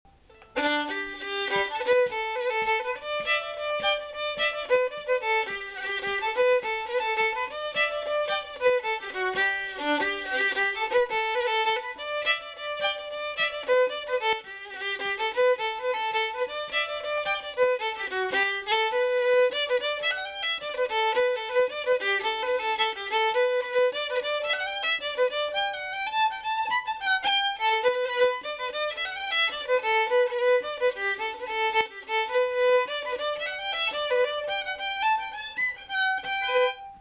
So, to help answer that question, I decided to do a little graphical analysis on The Marlin Spike comparing it to The Kesh Jig. Now, bear in mind that The Kesh Jig may be the most popular Irish tune in the world.